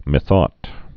(mĭ-thôt)